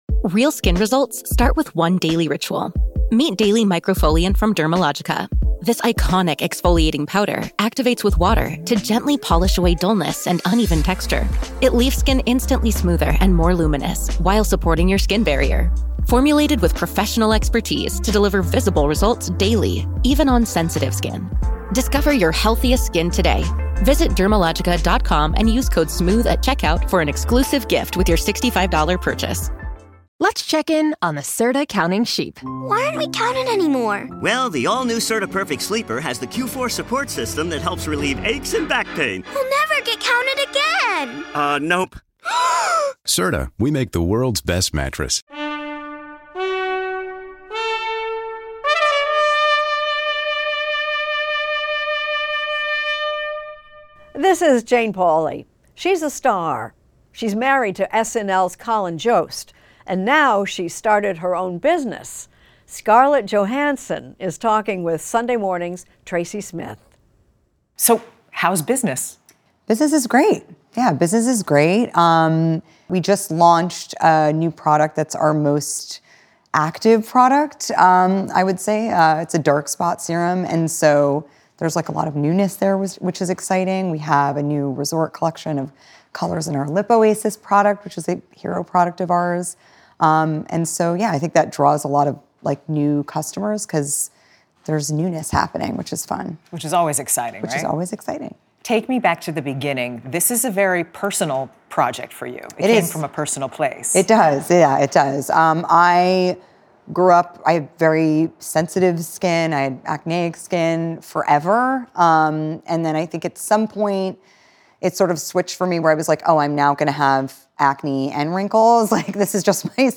Extended Interview: Scarlett Johansson Podcast with Jane Pauley
Extended Interview: Scarlett Johansson